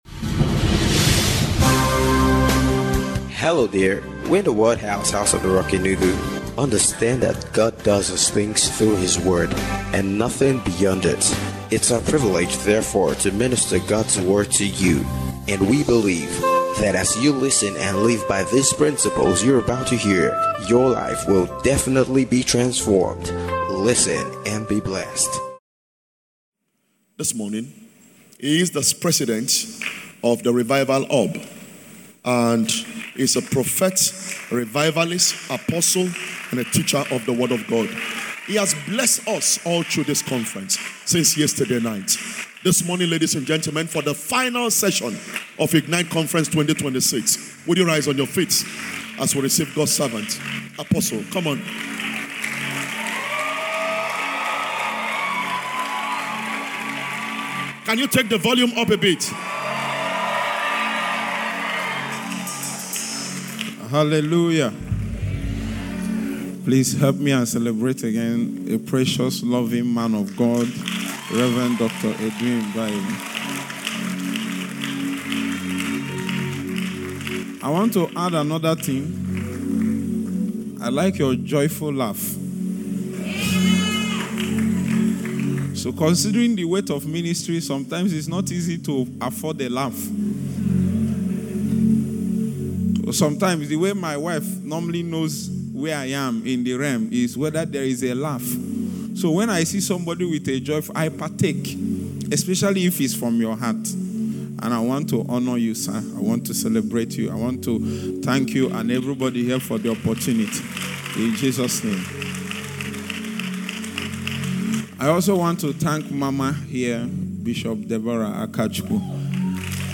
IGNITE CONFERENCE 2026- GRAND FINALE - SUNRISE SERVICE